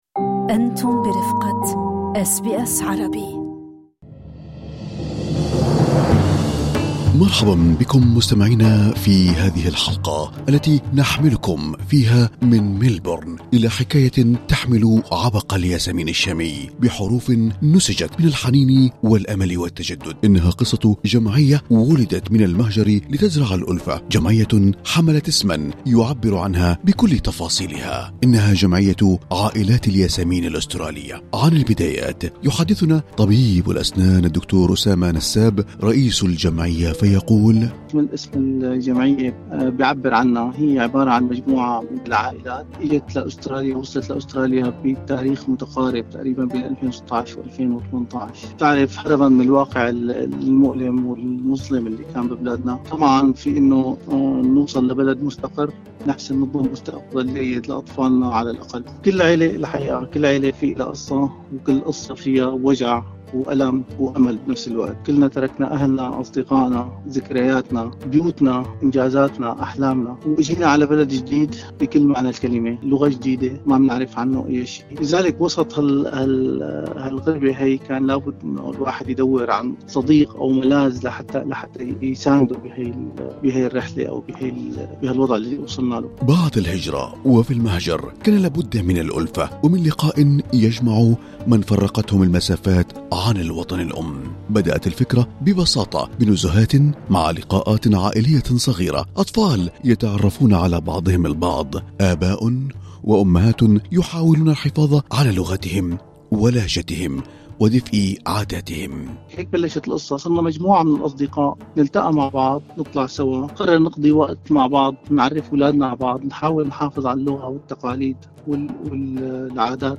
يحل عيد الميلاد المجيد يوم 25 من كانون الأول/ ديسمبر، وبهذه المناسبة التقت أس بي أس عربي مع قادة الكنائس في أستراليا الذين دعوا إلى فتح باب المحبة والتلاقي والسلام عبر رسائل تقدم بها سيادة المطران انطوان شربل طربية راعي الكنيسة المارونية في أستراليا، وسيادة المطران آميل نونا راعي أبرشية مار توما للكلدان في أستراليا ونيوزيلند، وسيادة المطران روبير رباط راعي أبرشية استراليا ونيوزلندا، أوقيانوسيا للروم الملكيين الكاثوليك